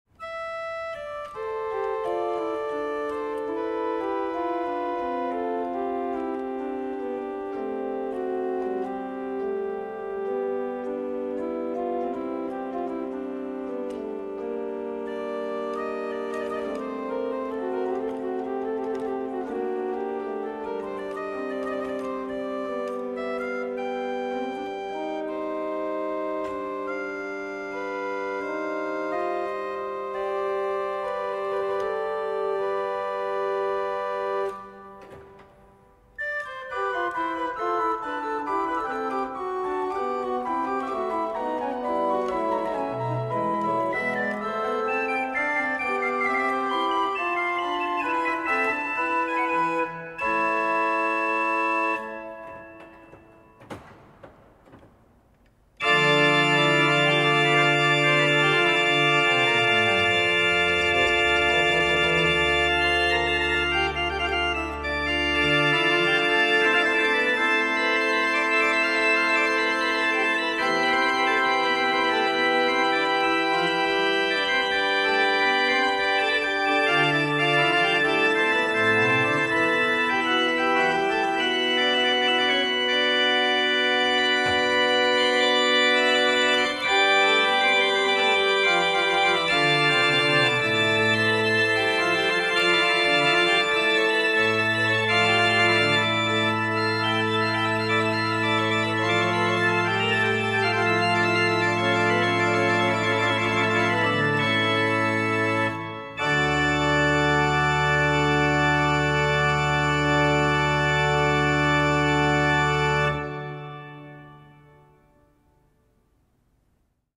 cappella del Cimitero
organo-serassi-monticelli.mp3